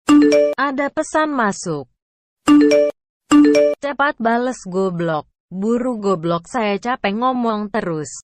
Notifikasi suara Google Ngegas
Kategori: Nada dering
nada-notifikasi-suara-google-ngegas-id-www_tiengdong_com.mp3